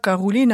[karulina]